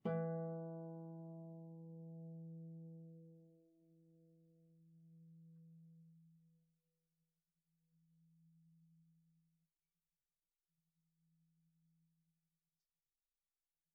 KSHarp_E3_mf.wav